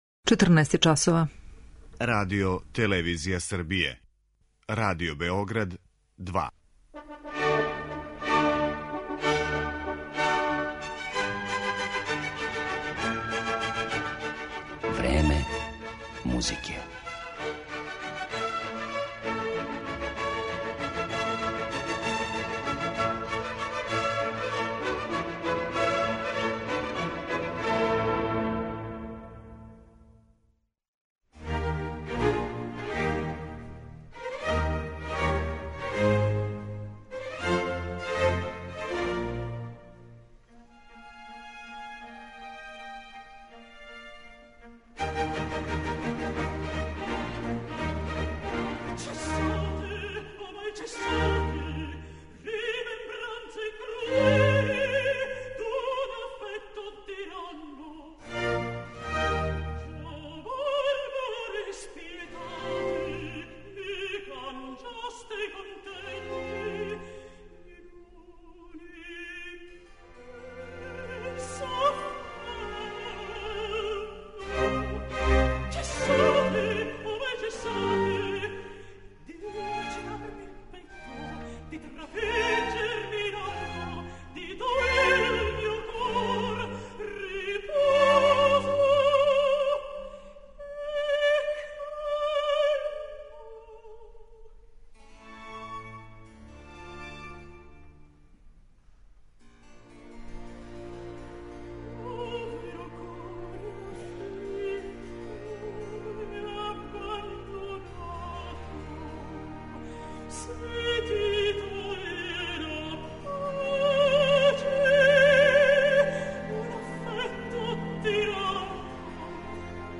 дела мајстора 18. века
прави контраалт